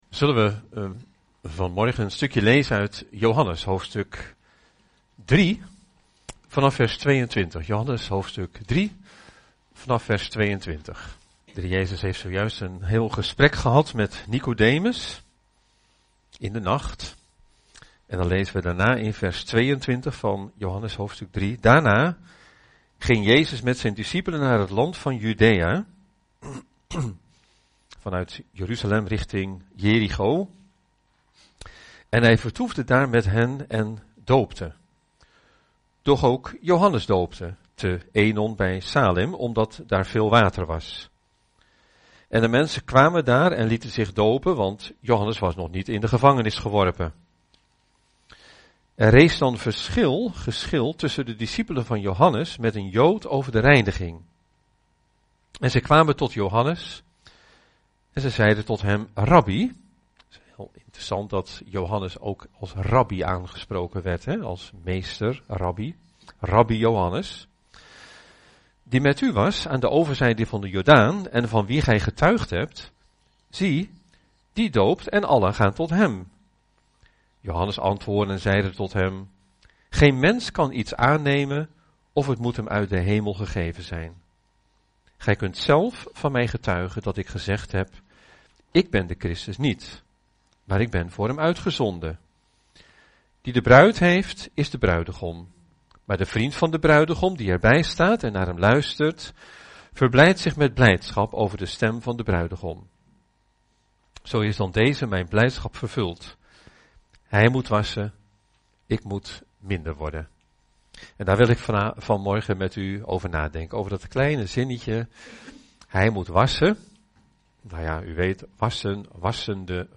Over deze preek